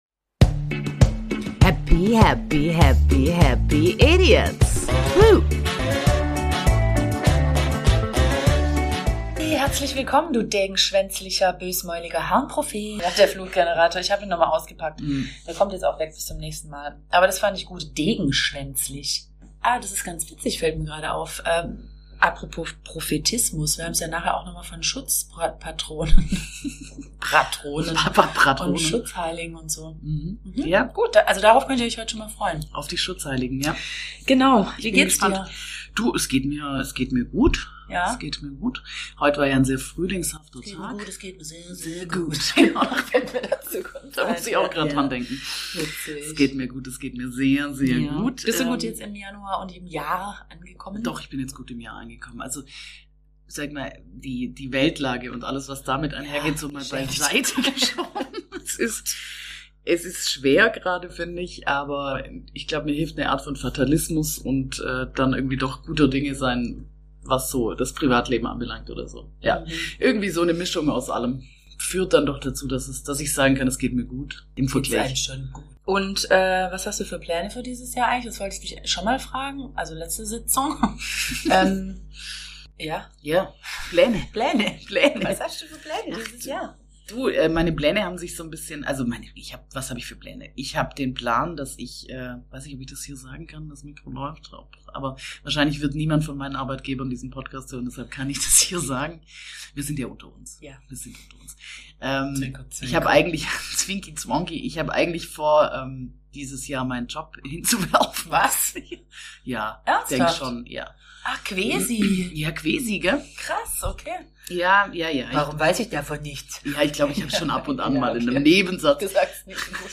Leider hat der Schutzpatron der Audio Technik nicht über uns gewacht, weshalb die Qualität der Aufnahme etwas gelitten hat.